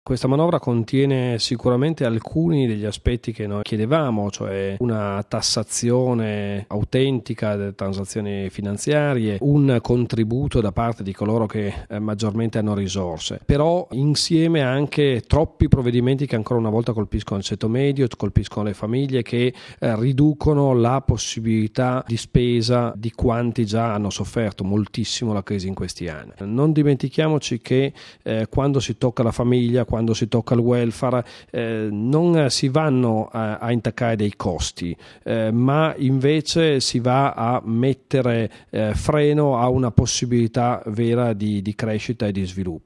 Ascoltiamolo al microfono